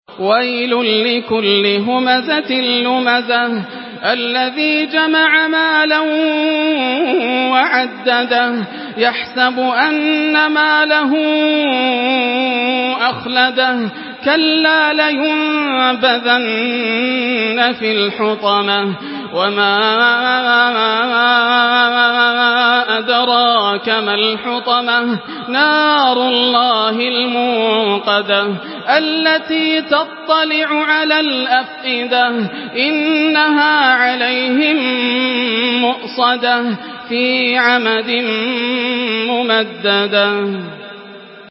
Surah আল-হুমাযাহ্ MP3 in the Voice of Yasser Al Dosari in Hafs Narration
Listen and download the full recitation in MP3 format via direct and fast links in multiple qualities to your mobile phone.